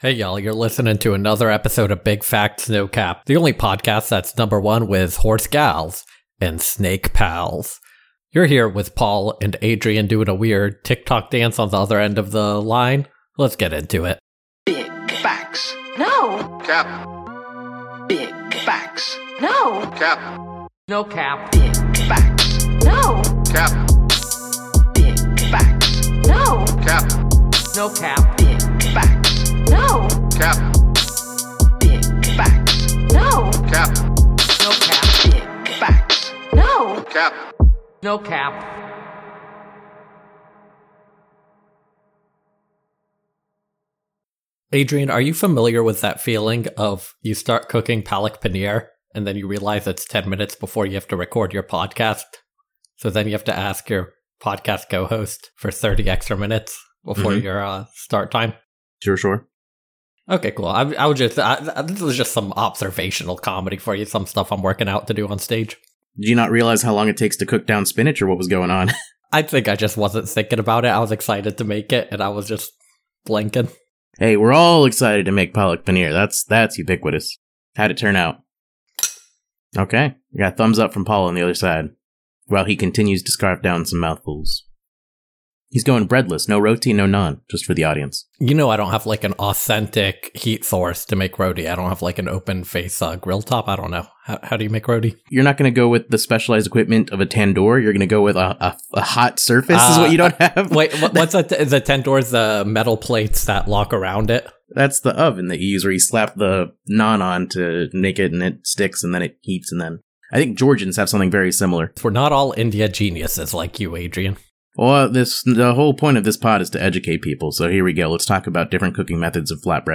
Big Facts, No Cap is an iPod broadcast in which two normal dudes with highly overlapping worldviews give advice to online strangers who will probably never hear it.
Comedy